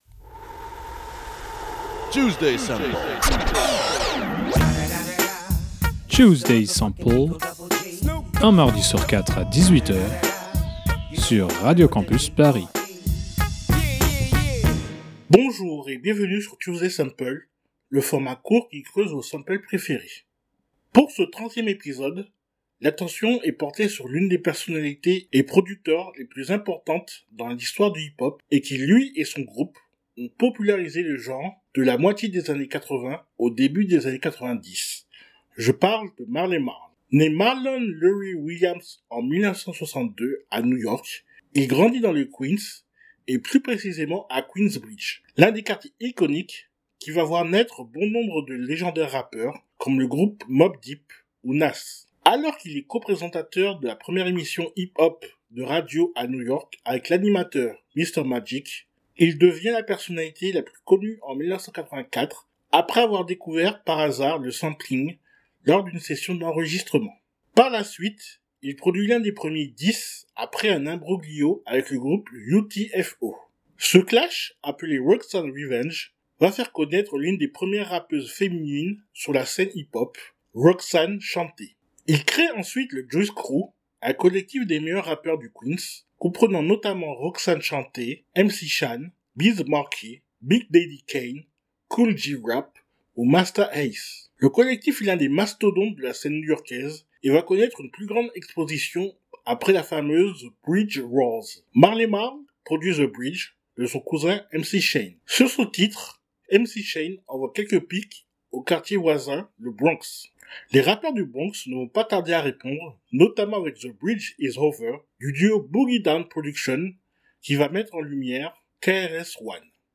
Type Musicale Hip-hop